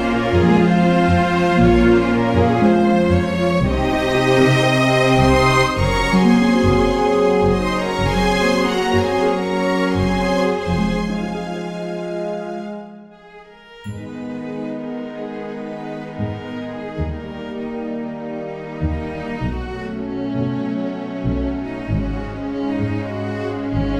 no Backing Vocals Musicals 2:36 Buy £1.50